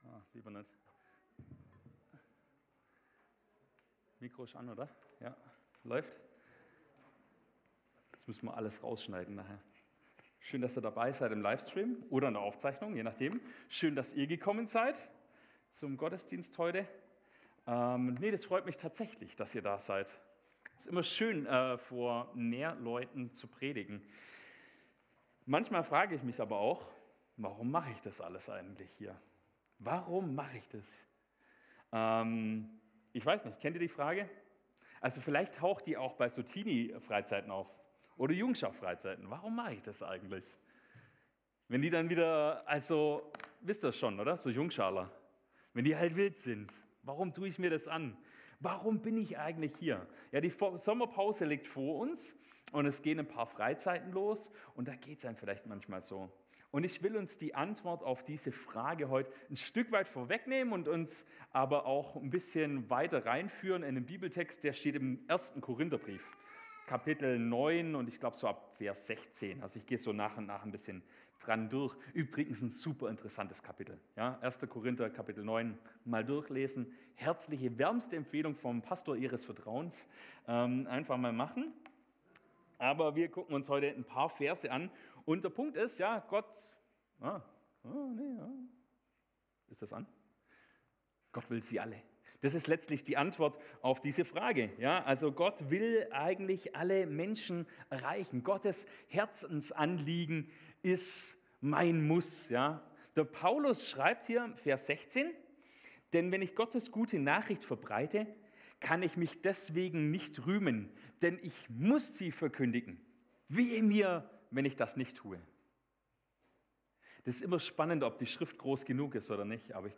Gottesdienst am 27.07.2025